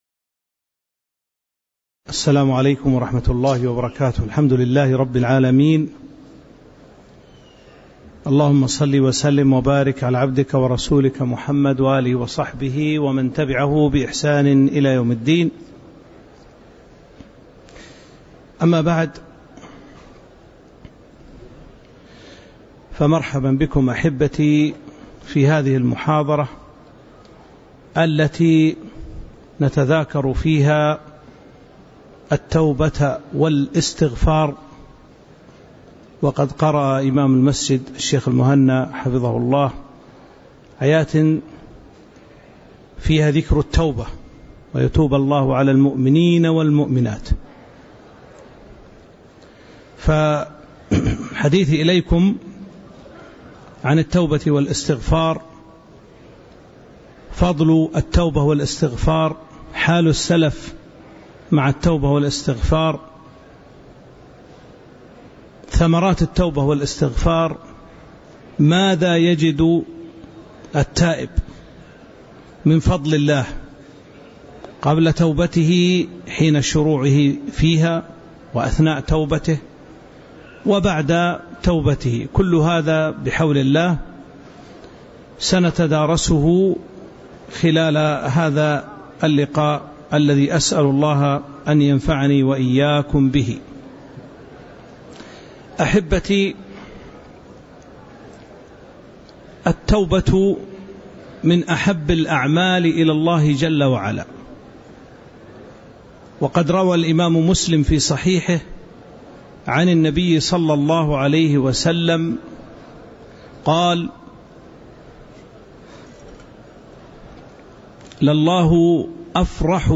تاريخ النشر ١٧ ذو القعدة ١٤٤٥ هـ المكان: المسجد النبوي الشيخ